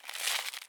Gunpowder.wav